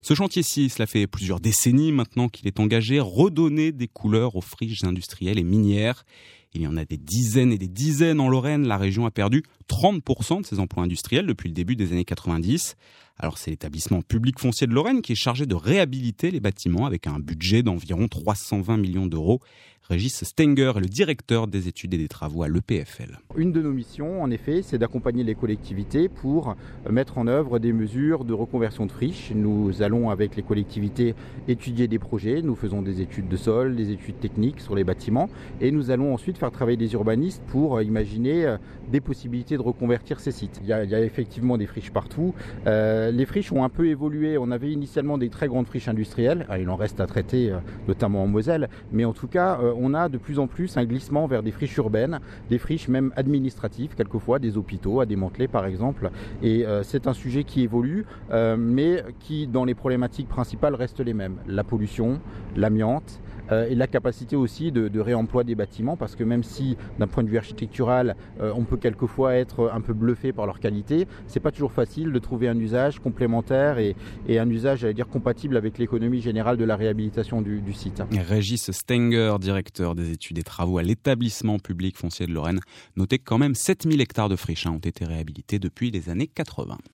France Bleu Lorraine (2 reportages)
Le 1 er juin 2017, l’EPFL avait donné rendez-vous à France Bleu Lorraine sur la friche SNCF à Jarville-la-Malgrange / Heillecourt. Il s’agissait d’aborder, sous un angle concret, le travail entrepris par l’établissement public depuis trente ans en matière de recyclage foncier.